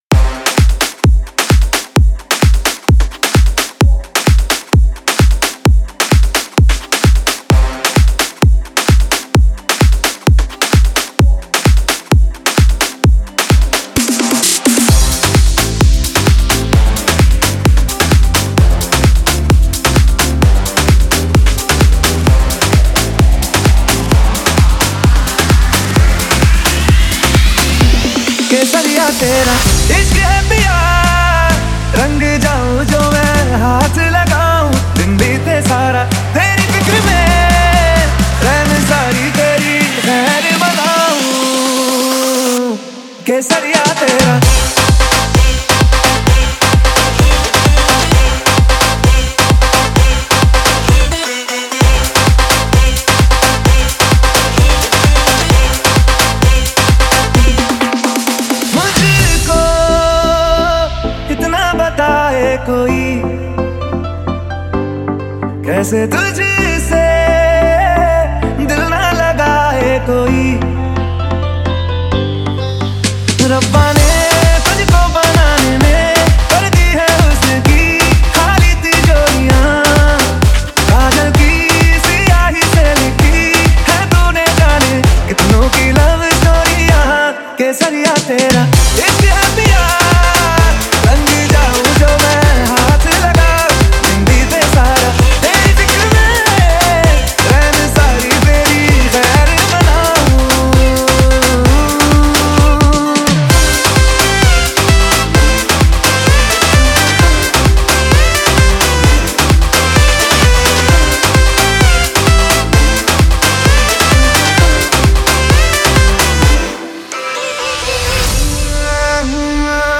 • Mix Type: Club Mix / High Bass
• Category: Bollywood DJ Remix
• 🔊 High Bass Boosted Audio
• 🔥 Romantic + Energetic Vibe
It combines romantic melody with powerful DJ beats.